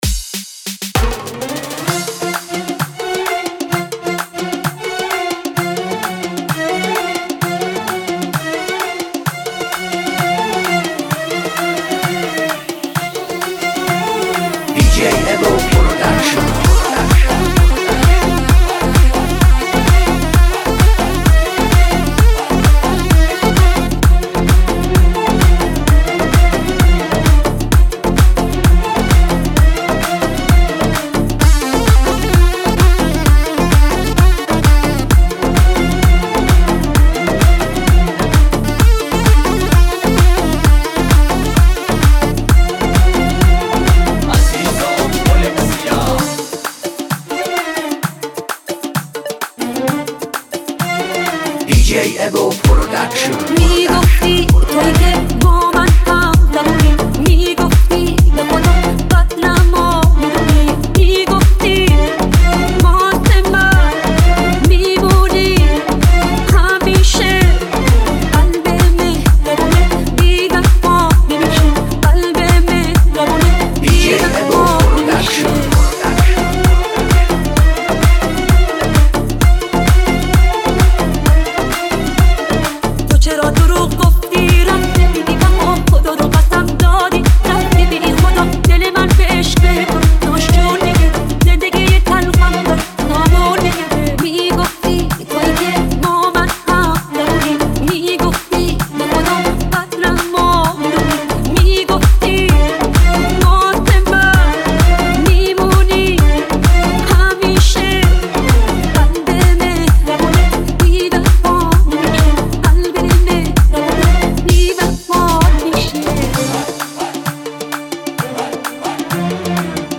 دانلود ریمیکس
882 بازدید ۷ اردیبهشت ۱۴۰۳ ریمیکس , ریمیکس فارسی